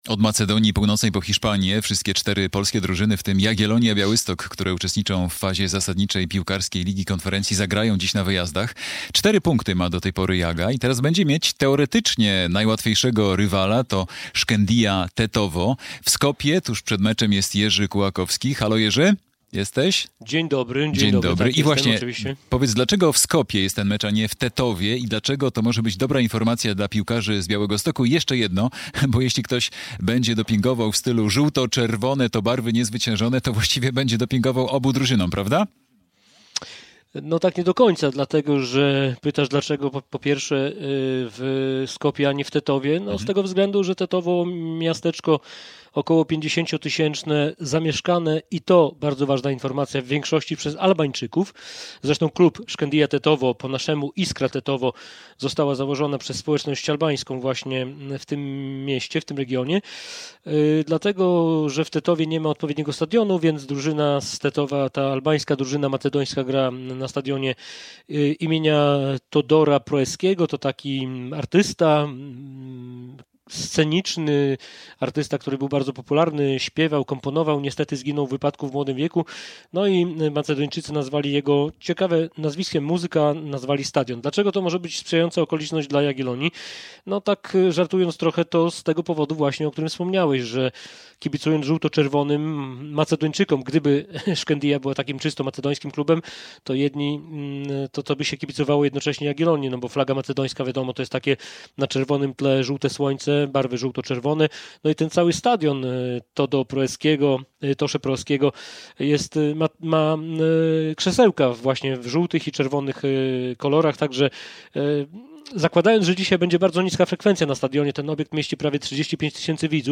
Przed meczem Shkendija Tetowo - Jagiellonia Białystok - relacja